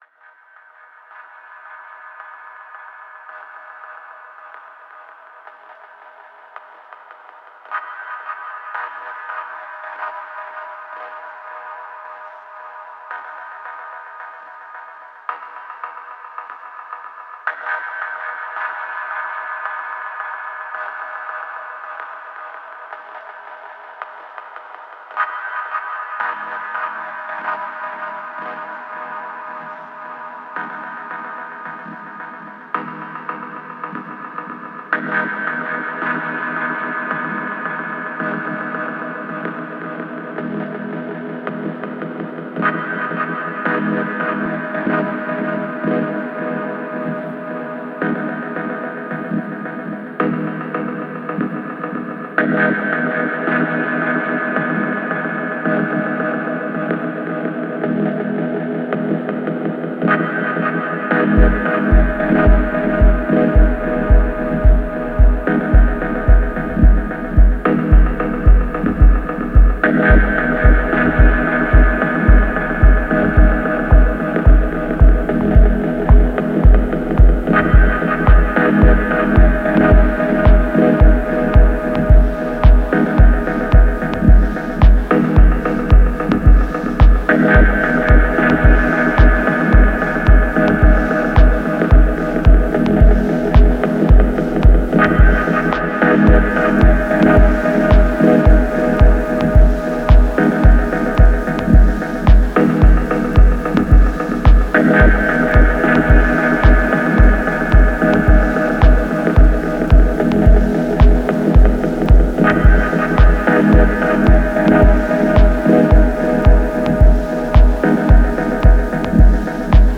Genre: Ambient/Dub Techno/Deep Techno.